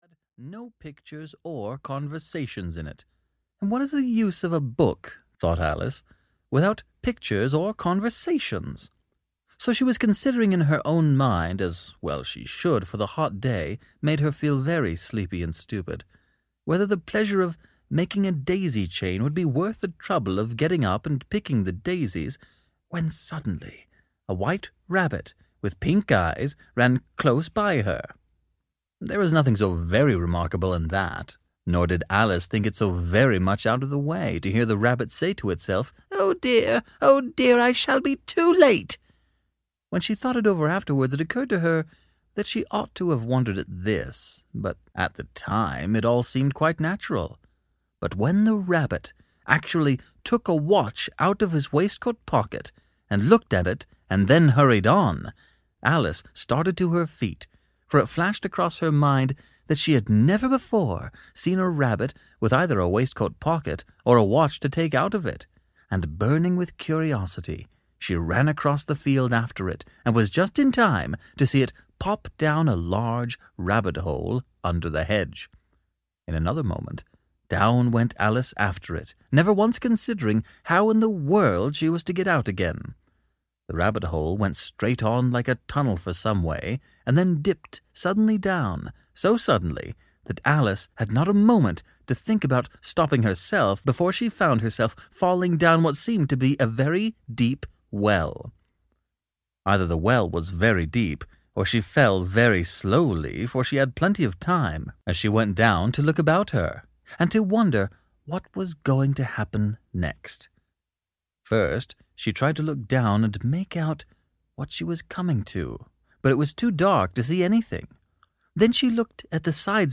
audiokniha
Ukázka z knihy